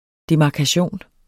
Udtale [ demɑkaˈɕoˀn ]